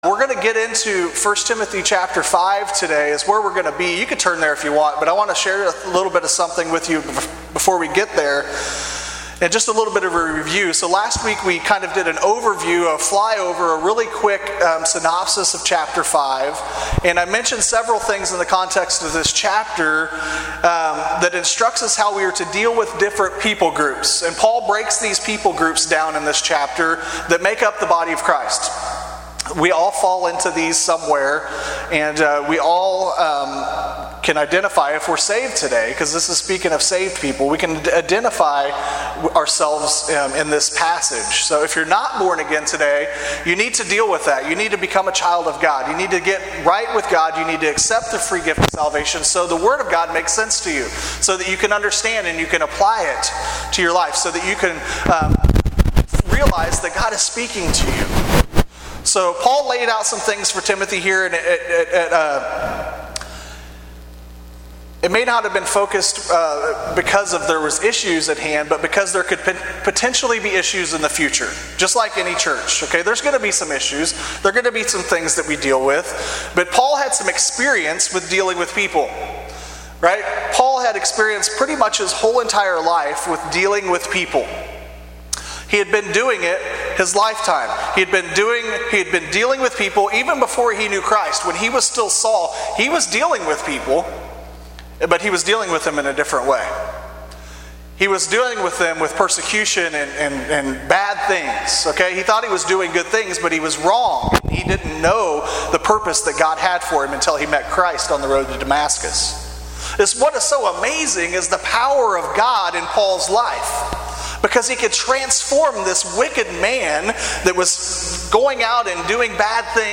In this sermon on 1Timothy 5:1-2